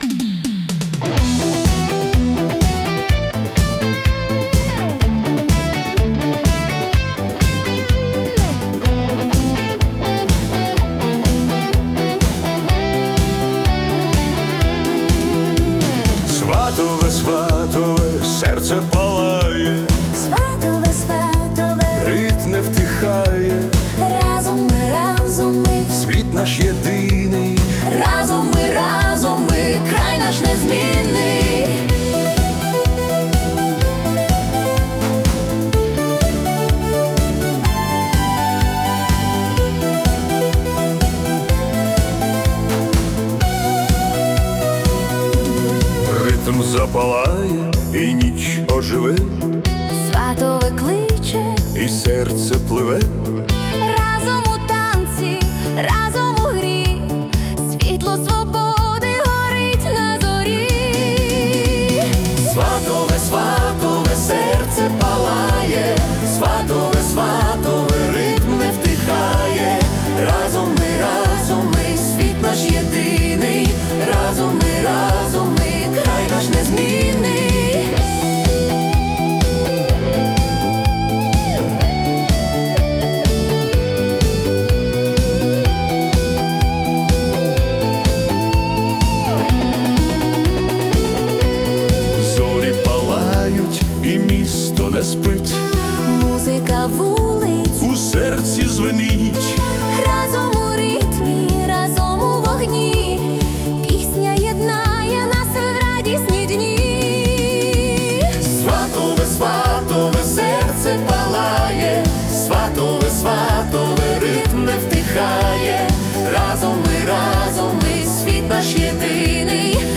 🎵 Жанр: Italo Disco / Pop
Вірусний і мелодійний приспів миттєво запам'ятовується.